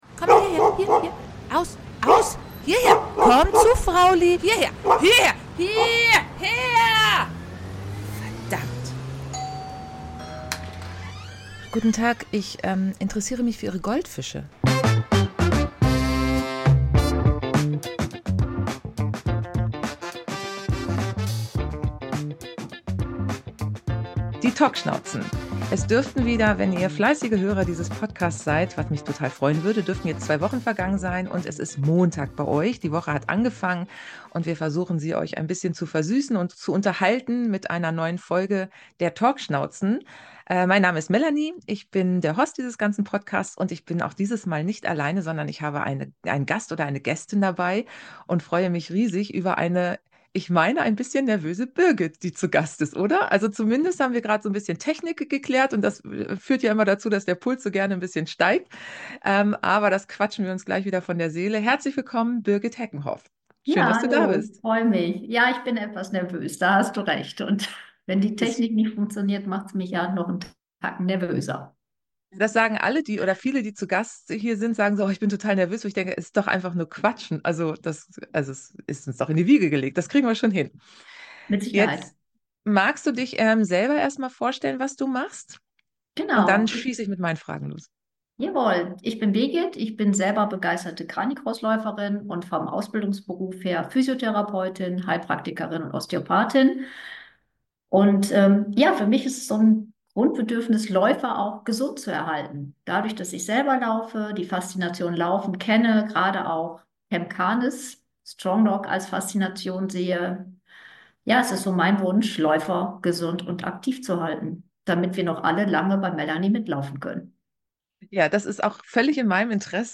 Ein wirklich spannendes Gespräch.